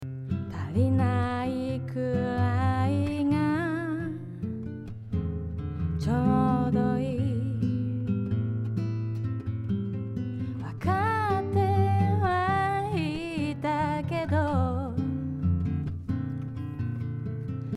EQ調整
ボーカルをこんな感じで調整してみました。
スッキリしてオリジナルよりも少し前に出てきた感じがします。